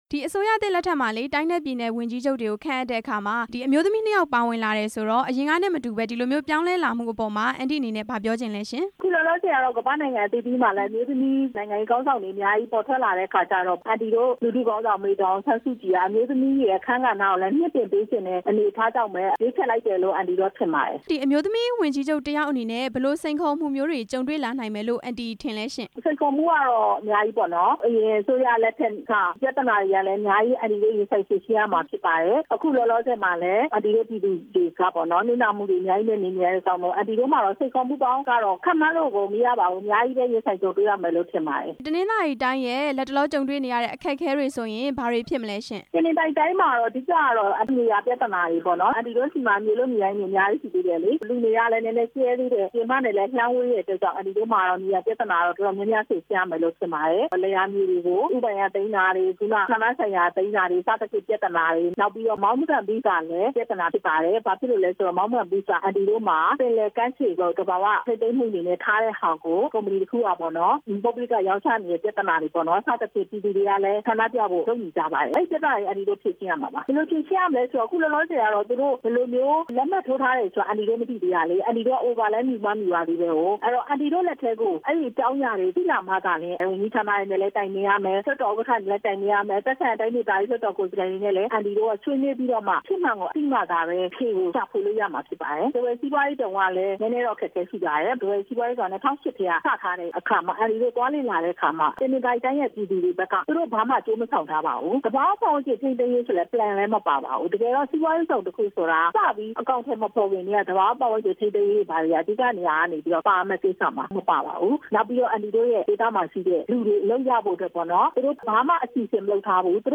တနင်္သာရီတိုင်းဝန်ကြီးချုပ် ဒေါက်တာလဲ့လဲ့မော်နဲ့ မေးမြန်းချက်